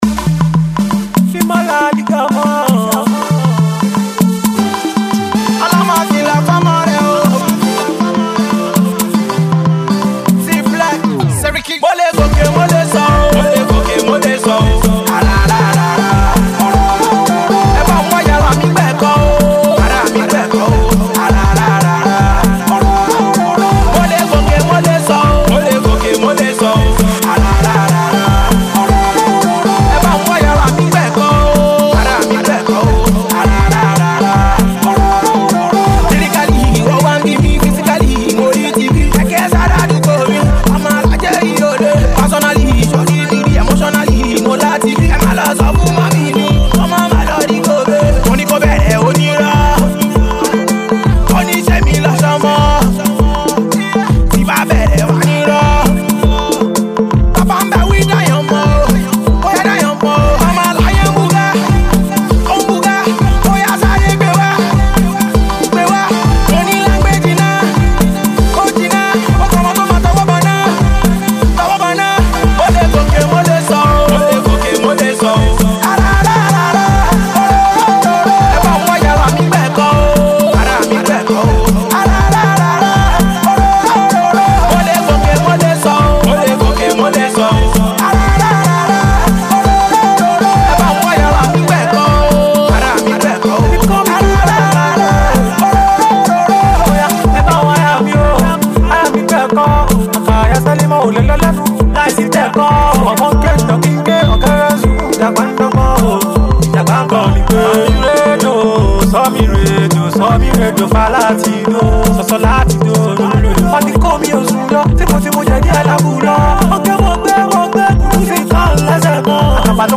Pop
Street anthem
rapper